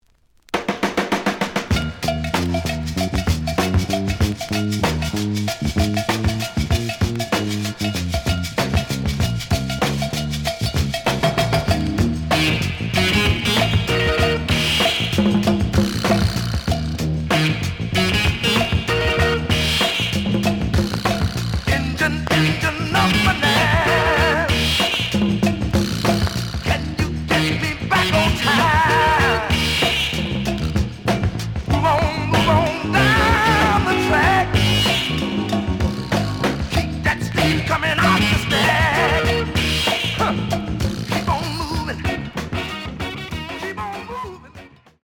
The audio sample is recorded from the actual item.
●Genre: Funk, 70's Funk
Some click noise on A side due to scratches.